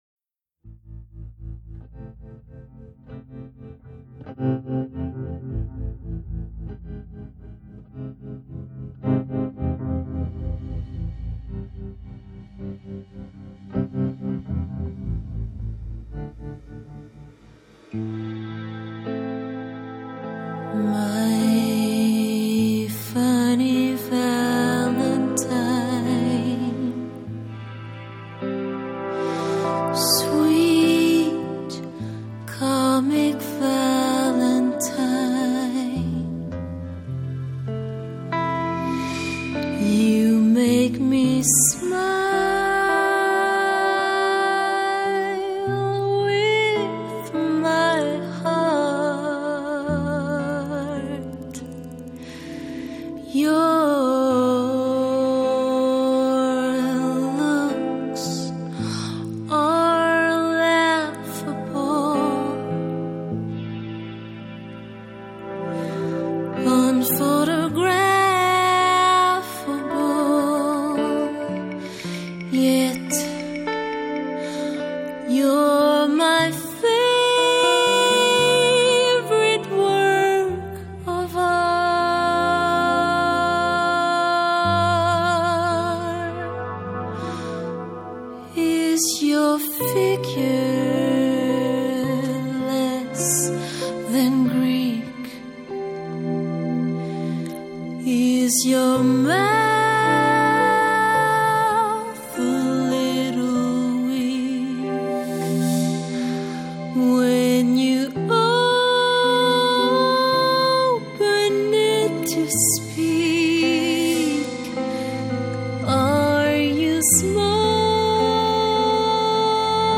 The group is a quartet
alto saxophonist